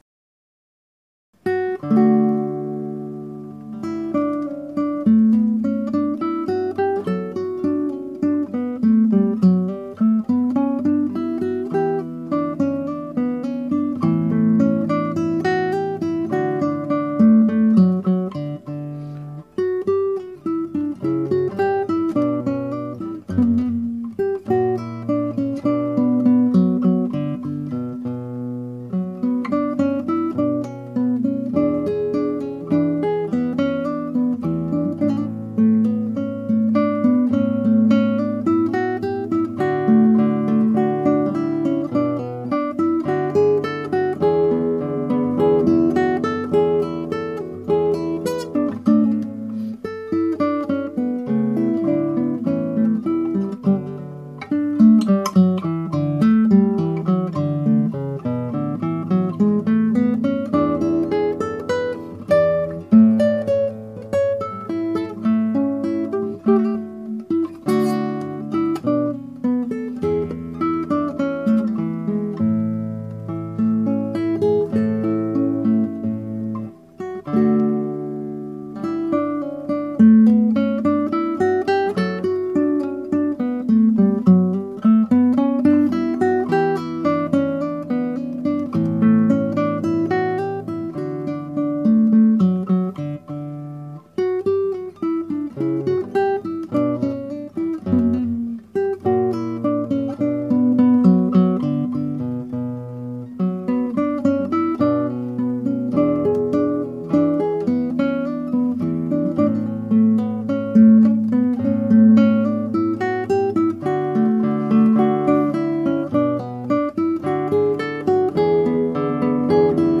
(アマチュアのクラシックギター演奏です [Guitar amatuer play] )
再録1は少し早めにさらっとよりインテンポで弾きました。
また、モルデントやトリルがいまいち。
原調はト長調ですがギター版はニ長調です。
もっとレガートに弾きたかったのですが今後の課題とします。また一つひとつの音の強さのコントロールが不十分でとんがったりひっこんだりが多いのも課題です。